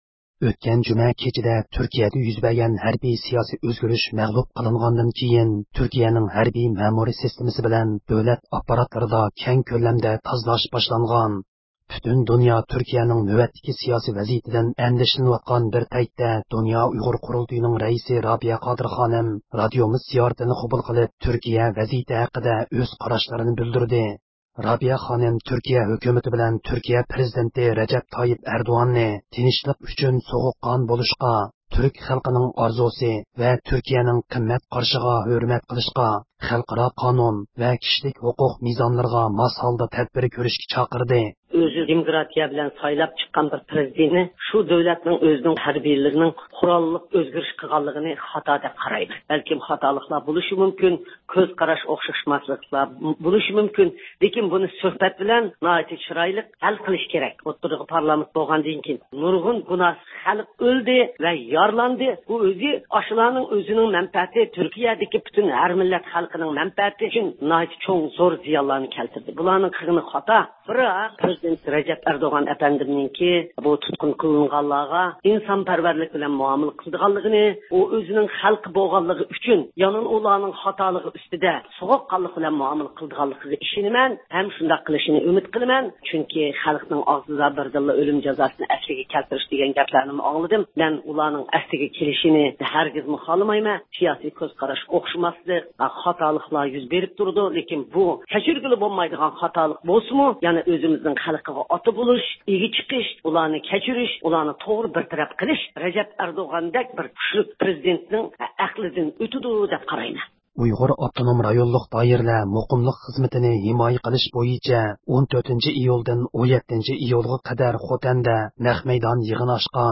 پۈتۈن دۇنيا تۈركىيەنىڭ نۆۋەتتىكى سىياسىي ۋەزىيىتىدىن ئەندىشىلىنىۋاتقان بىر پەيتتە، دۇنيا ئۇيغۇر قۇرۇلتىيىنىڭ رەئىسى رابىيە قادىر خانىم رادىيومىز زىيارىتىمىزنى قوبۇل قىلىپ، تۈركىيە ۋەزىيىتى ھەققىدە ئۆز قاراشلىرىنى بىلدۈردى.
بۇ ھەقتە رادىيومىز زىيارىتىنى قوبۇل قىلغان بىر نەپەر ھۆكۈمەت خادىمى ۋە يەرلىك ئاھالىلەر بۇ يىللىق كۇرسنىڭ ئېچىلىش ۋاقتى ۋە كۇرسنىڭ مەقسىتى ھەققىدە سوئاللىرىمىزغا جاۋاب بەردى.